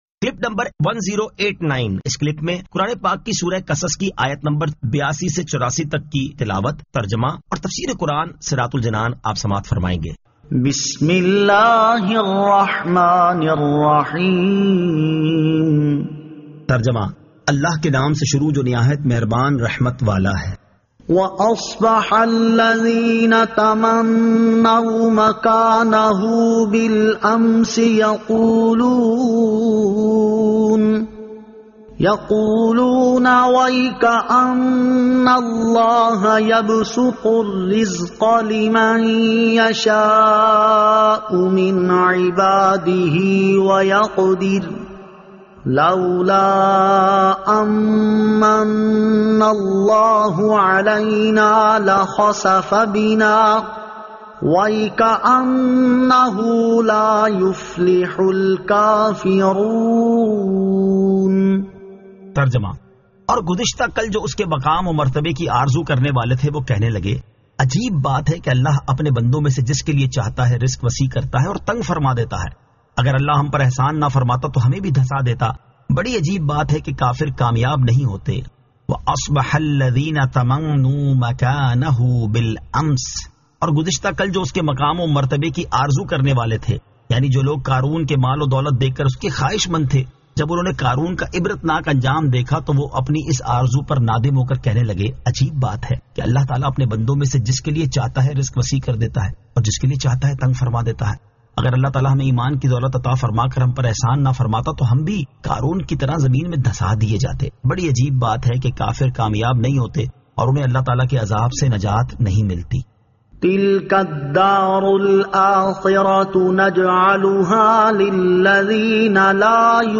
Surah Al-Qasas 82 To 84 Tilawat